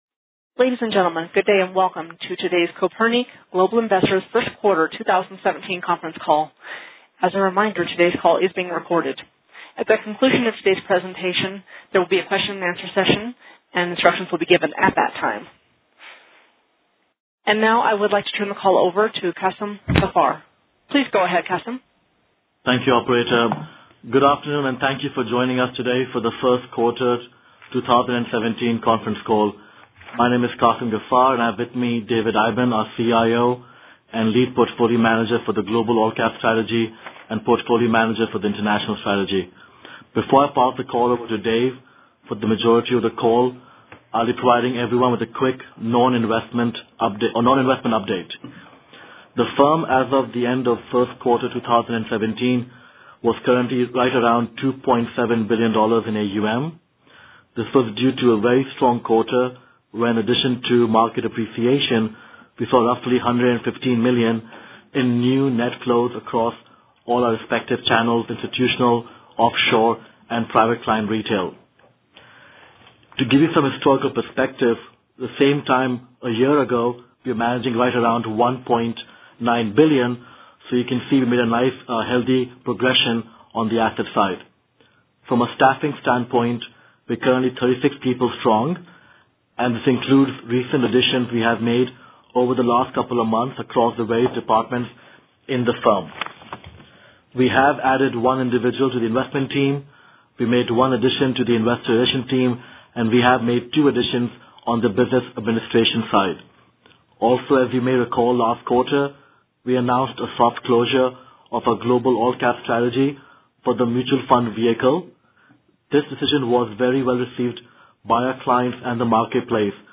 Q1 2017 Conference Call - Kopernik Global Investors